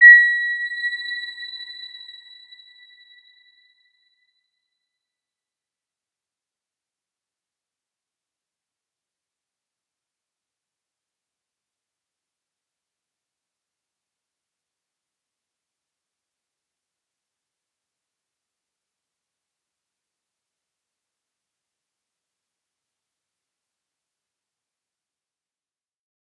Round-Bell-B6-p.wav